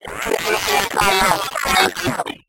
边疆2》机器人语音 " 准备受死吧 01
描述： 声乐线的灵感来自于Borderlands的机器人装载机2.每个都被标准化为3dBFS，但没有采用限制。
标签： 机器人语音 声音 Borderlands2 机器人 准备到死 处理 装载机 游戏人声 机器人 变形的 变形 科幻 无主-2 游戏声音
声道立体声